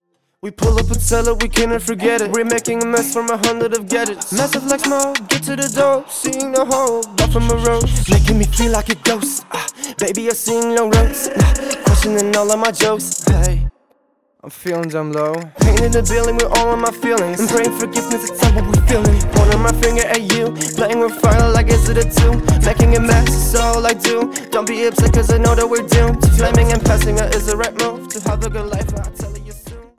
Vocal / Editieren